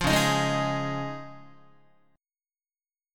Dbadd9 chord